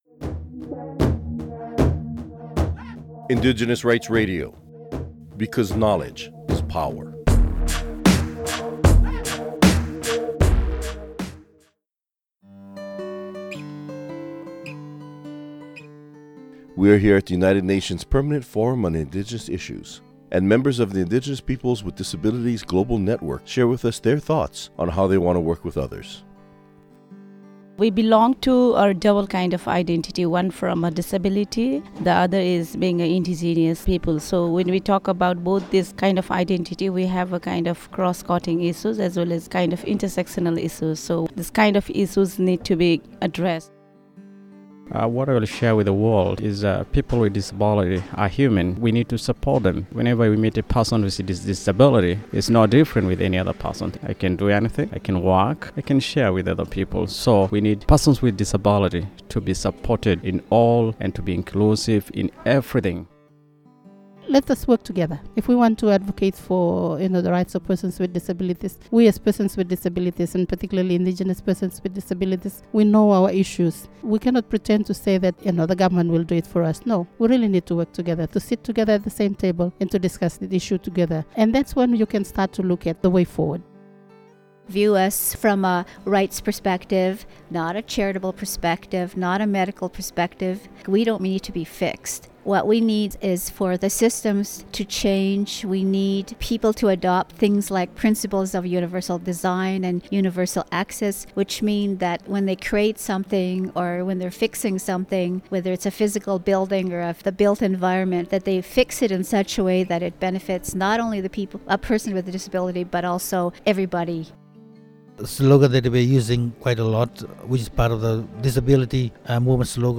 Recording Location: UNPFII 2015
Description: Interview at the United Nations Permament Forum on Indigenous Peoples, May 2015 in New York. Listen to a members of the Indigenous Peoples Global Network speak about how they want to be included as Indigenous Peoples with Disabilities in the broader movement.
Type: Interview
0kbps Stereo